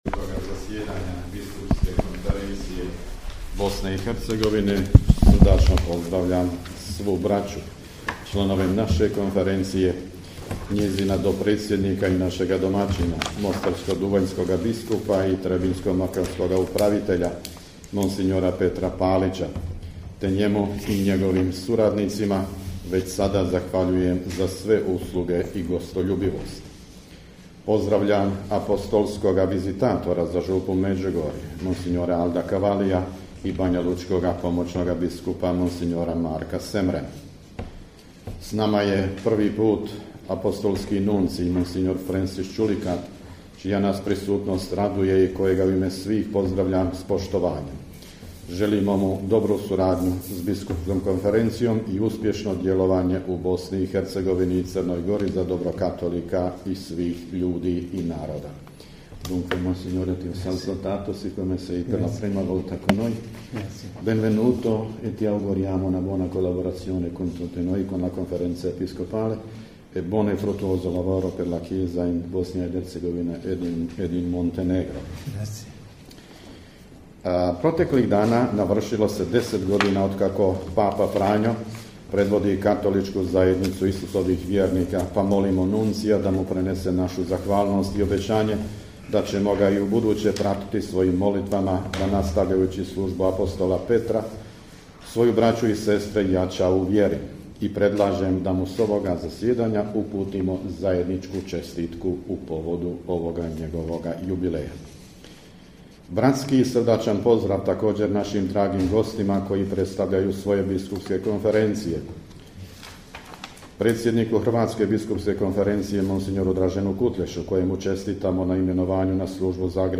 AUDIO: POZDRAV NADBISKUPA VUKŠIĆA NA POČETKU 86. REDOVITOG ZASJEDANJA BISKUPSKE KONFERENCIJE BOSNE I HERCEGOVINE
Biskupska konferencija Bosne i Hercegovine, u jutarnjim satima 21. ožujka 2023. u prostorijama Biskupskog ordinarijata u Mostaru, započela je svoje 86. redovito zasjedanje pod predsjedanjem nadbiskupa metropolita vrhbosanskog i apostolskog upravitelja Vojnog ordinarijata u BiH mons. Tome Vukšića, predsjednika BK BiH.